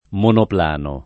monoplano
[ monopl # no ]